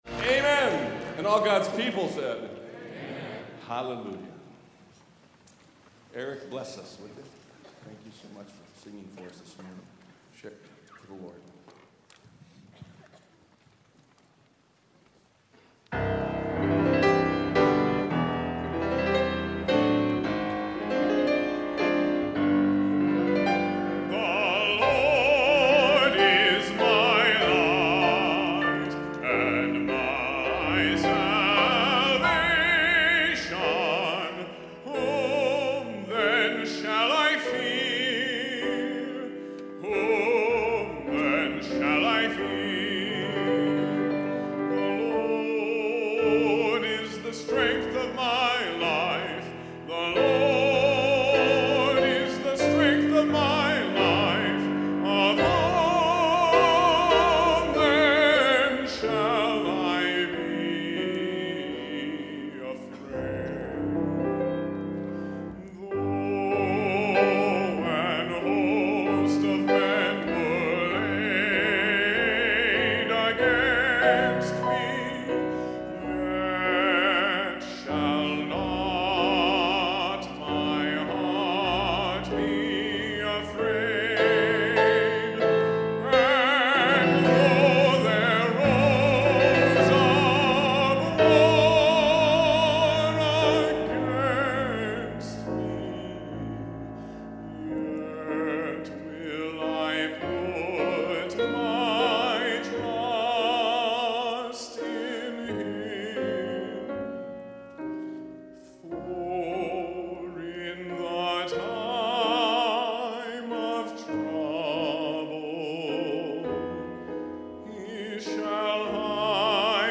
SWBTS Chapel Audio